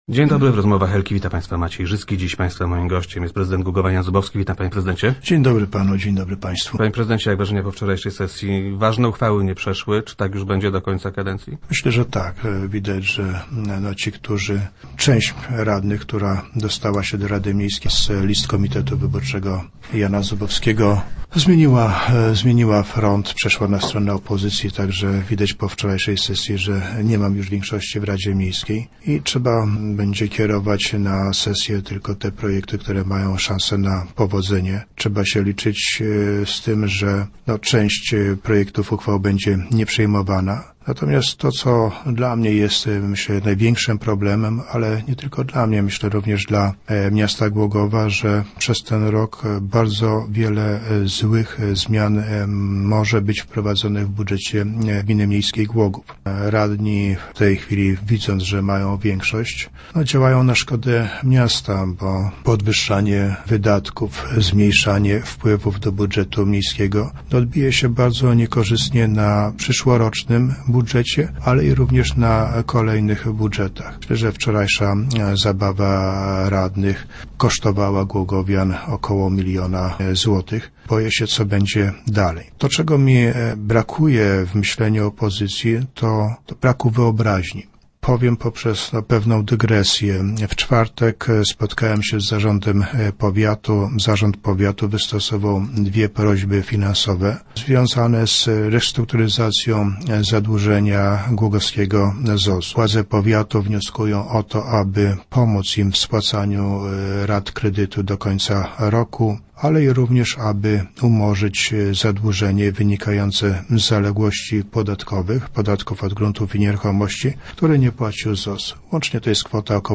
- Takich obietnic było już wiele. Żadna nie została zrealizowana – powiedział prezydent Jan Zubowski, który był gościem Rozmów Elki.